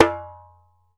ASHIKO 4 04L.wav